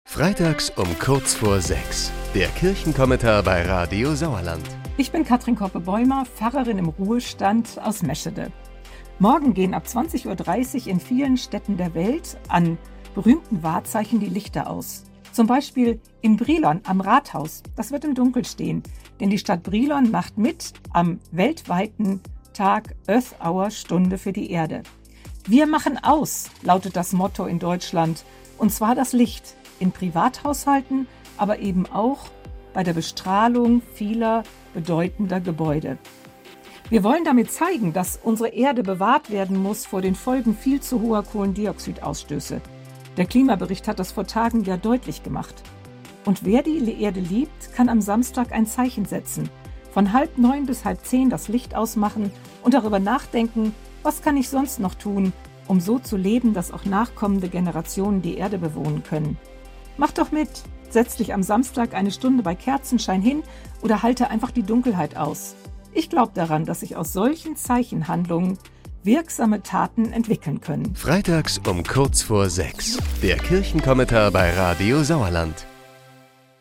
Kirchenvertreter greifen aktuelle Themen auf, die uns im Sauerland bewegen.